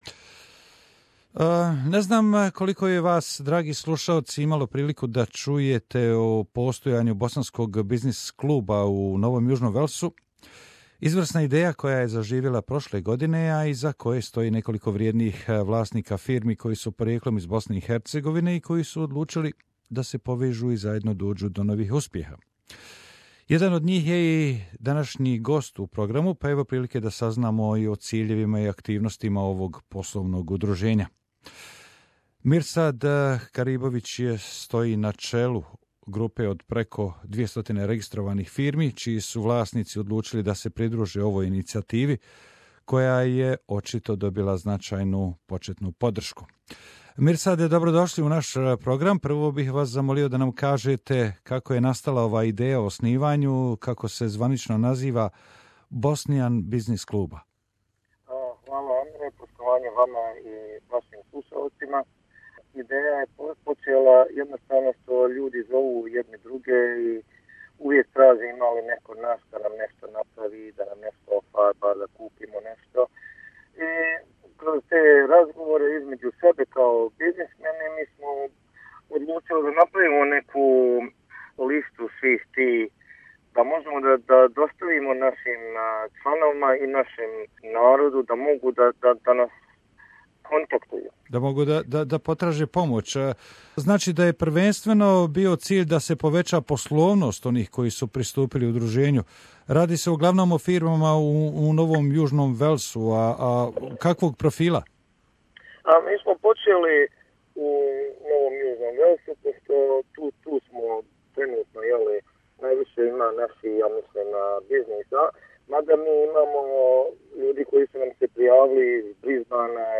Udruženje Bosnian Business Club, koje je nastalo u Novom Južnom Velsu nastoji da sa promocijom svoga rada proširi interese i obuhvati što više malih privrednika koji žive u Australiji a porijeklom su iz Bosne i Hercegovine U razgovoru za naš program